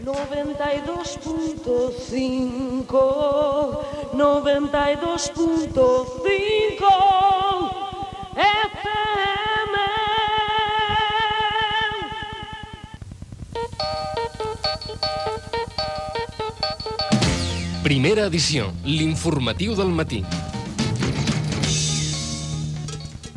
2acab215b8123f44bedc388ed43b68546df759d3.mp3 Títol Ràdio L'Hospitalet Emissora Ràdio L'Hospitalet Titularitat Pública municipal Nom programa Primera edició Descripció Freqüència i careta del programa.